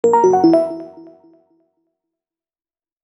GameLose.wav